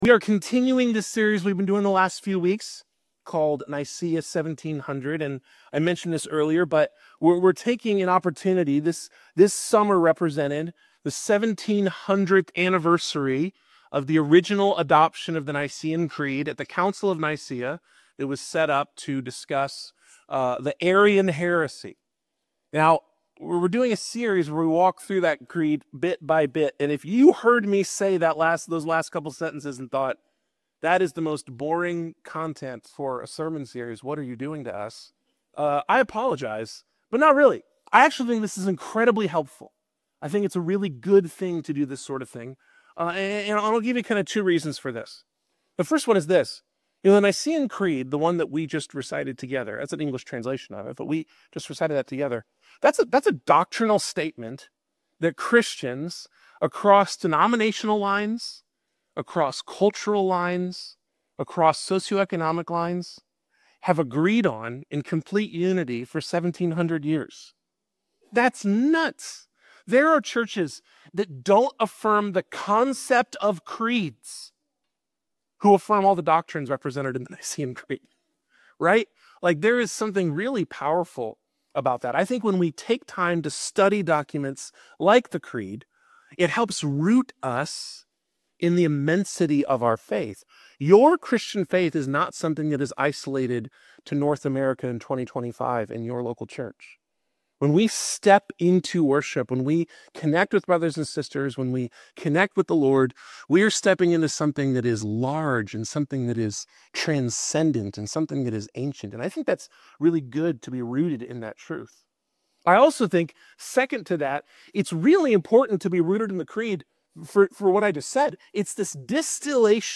Whether you're grappling with church hurt or seeking to deepen your faith, this sermon offers encouragement and a call to embrace your unique role in God's family.